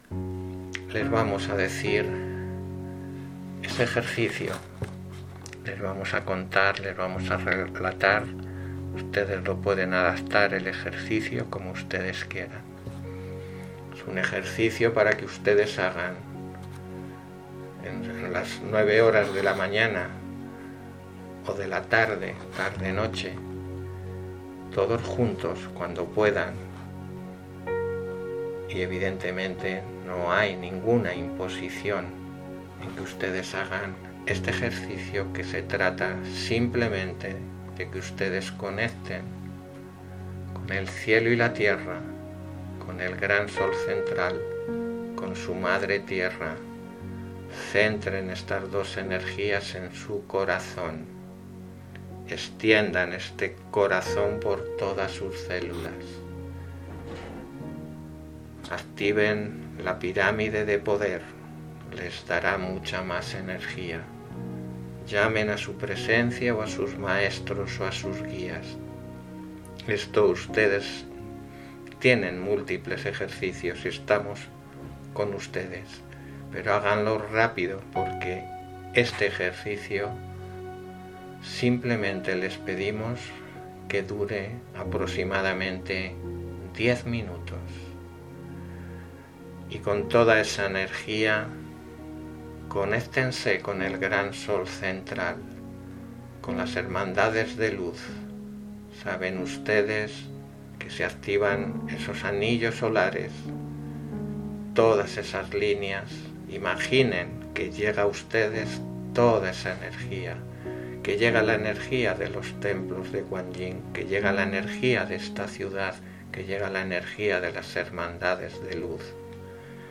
MEDITACIONES MP3